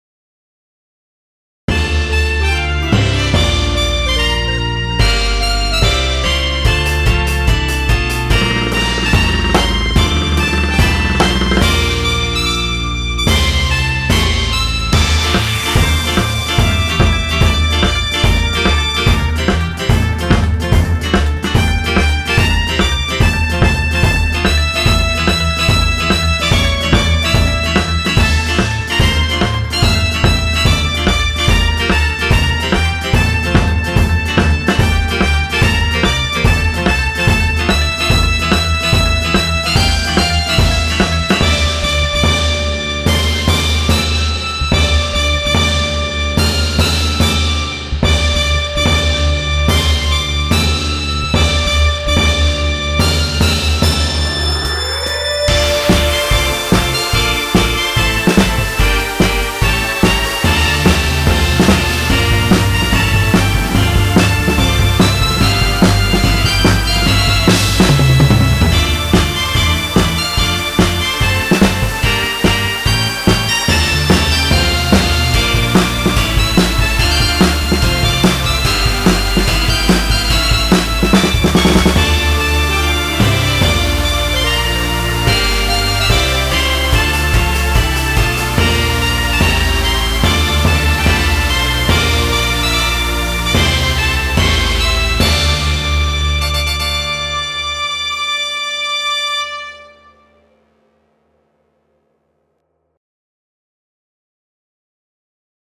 ショート暗い激しい